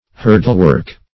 hurdlework - definition of hurdlework - synonyms, pronunciation, spelling from Free Dictionary
Hurdlework \Hur"dle*work`\, n. Work after manner of a hurdle.